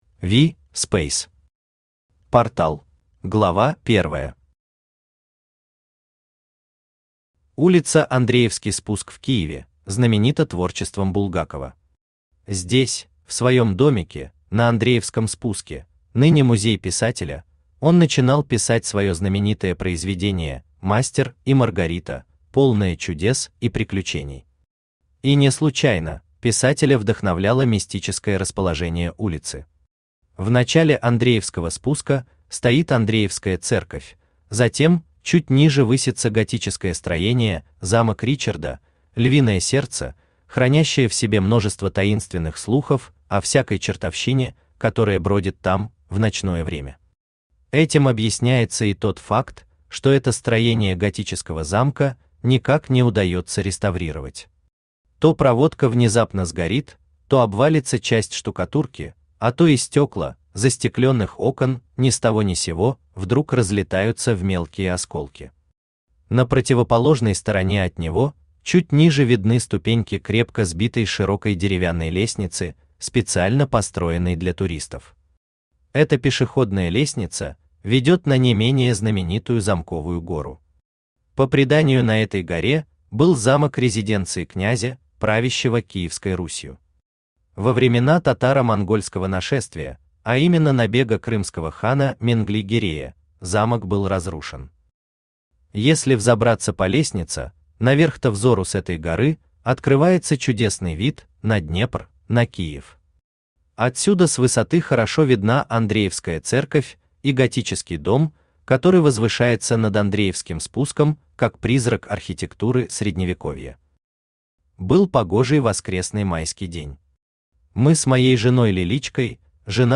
Аудиокнига Портал | Библиотека аудиокниг
Aудиокнига Портал Автор V. Speys Читает аудиокнигу Авточтец ЛитРес.